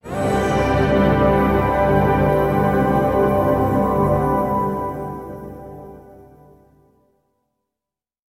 Как будто включился большой экран на дефиле